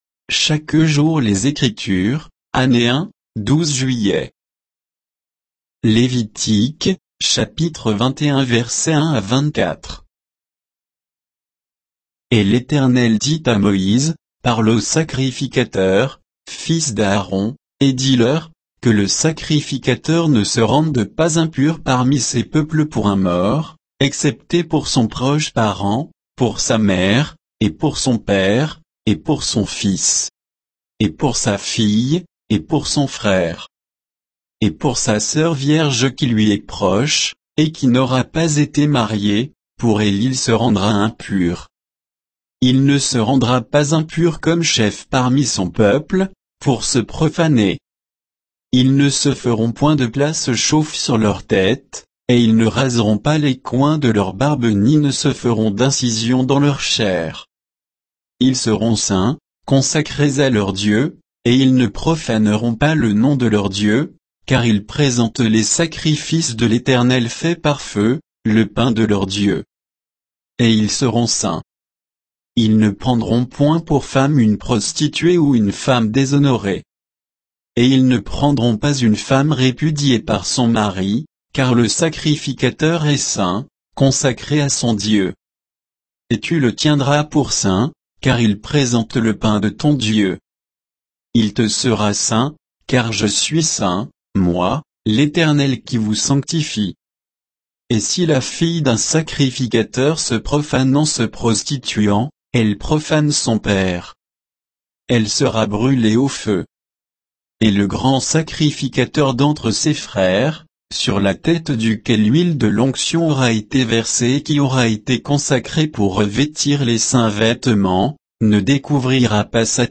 Méditation quoditienne de Chaque jour les Écritures sur Lévitique 21